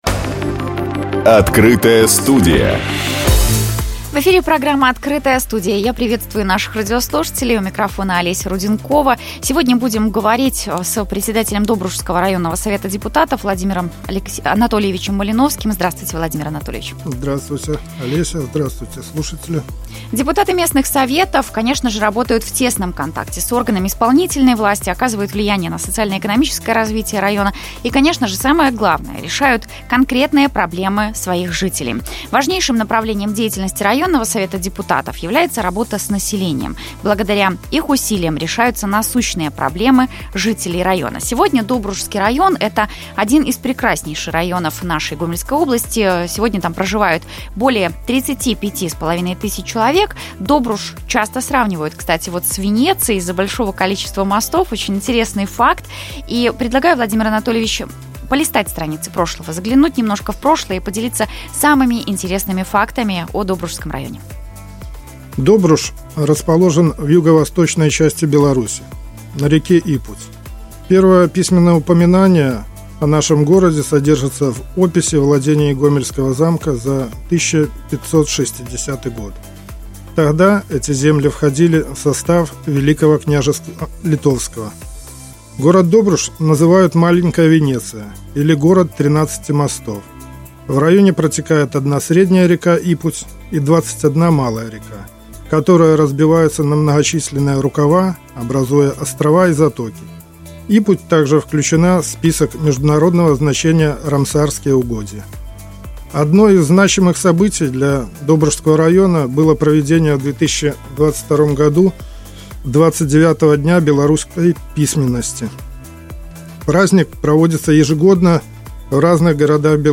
Открытая студия. Горячая линия Гомельского облисполкома (радиостанция «Гомель «Плюс»)
В гостях — председатель Добрушского районного Совета депутатов Владимир Анатольевич Малиновский.